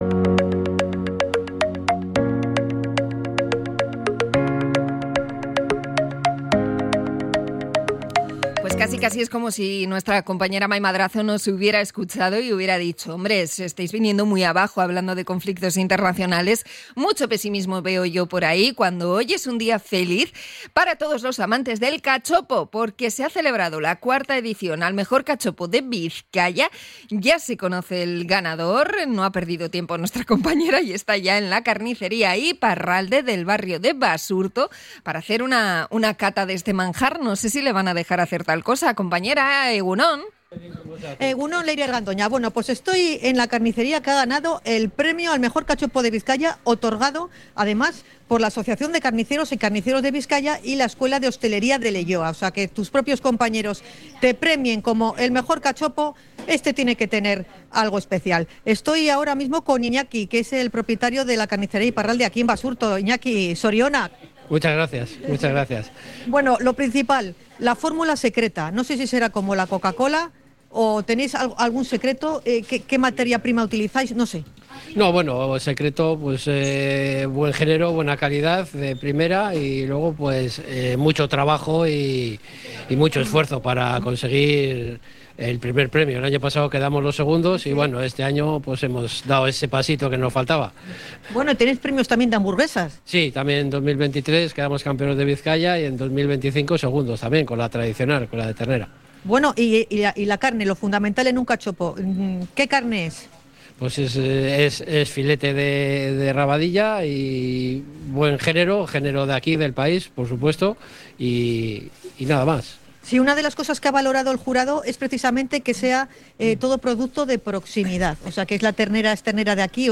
Hablamos